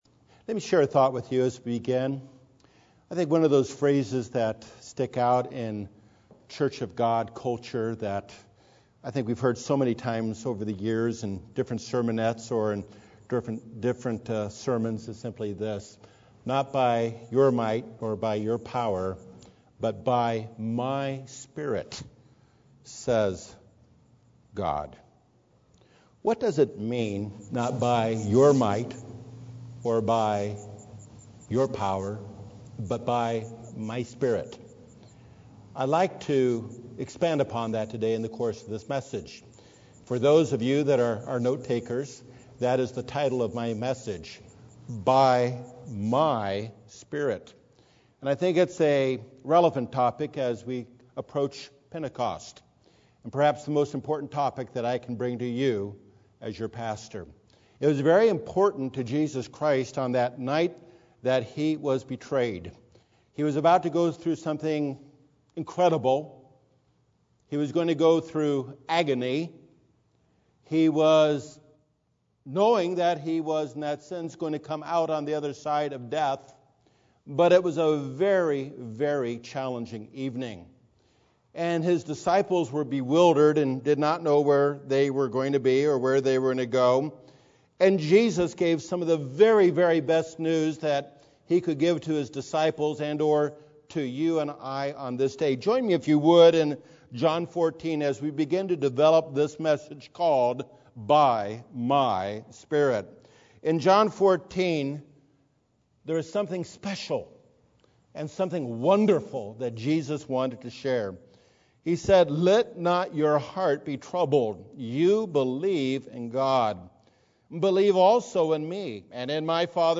Given during the Pentecost season, this message examines how God will accomplish His purpose and execute His plan of salvation, not by our power, but through His Holy Spirit.